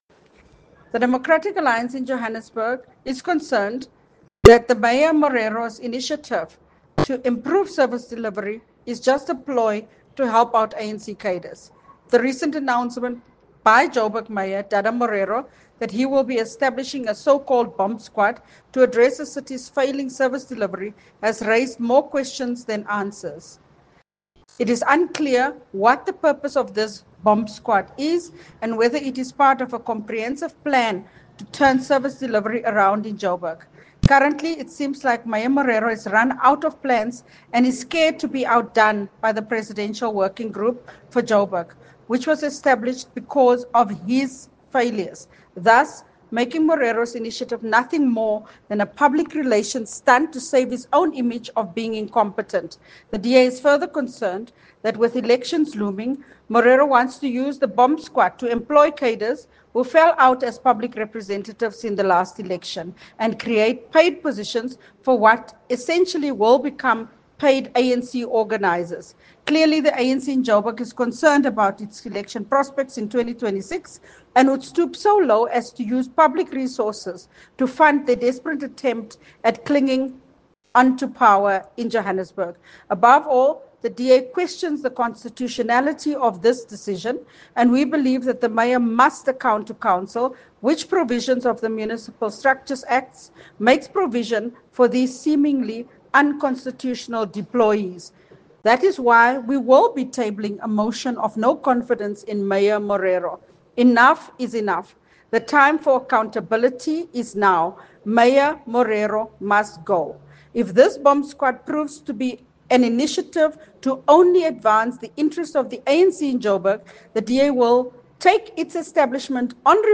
Note to Editors: Please find an English soundbite by Cllr Belinda Kayser-Echeozonjoku